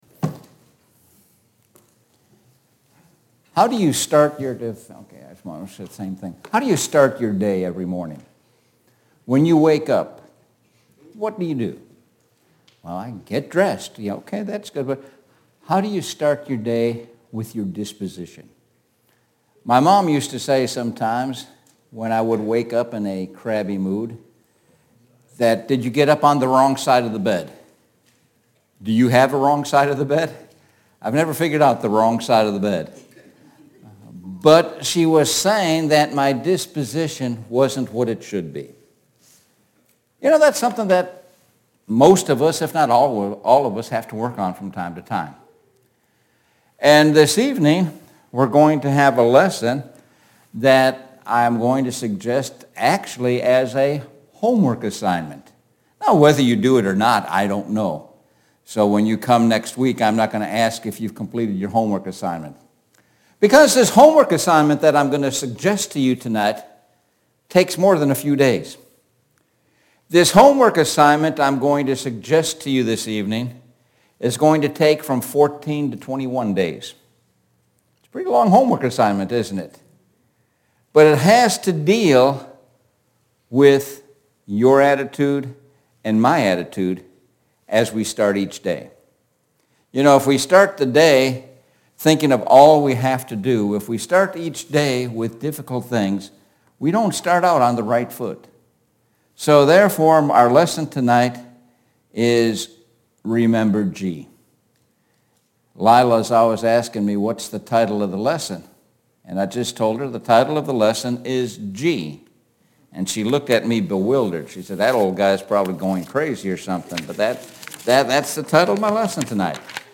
Sun PM Sermon – Remember G
Lakeland Hills Blvd Church of Christ